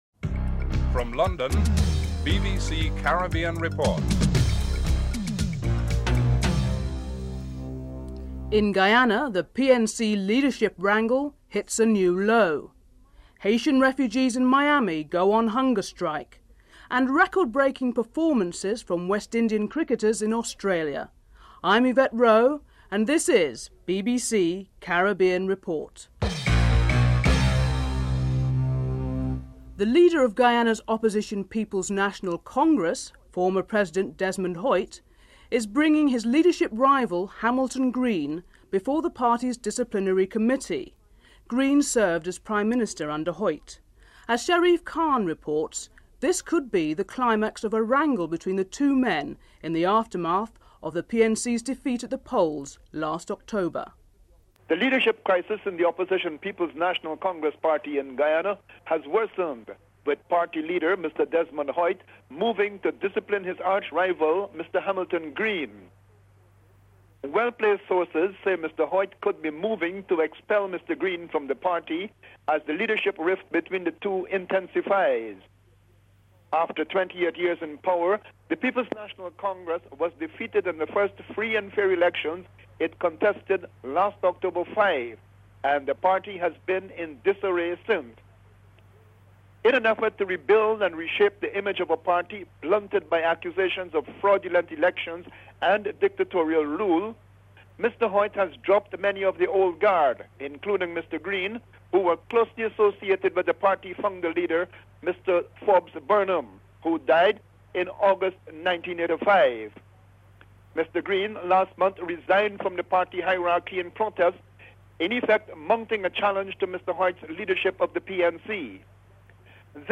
1.Headlines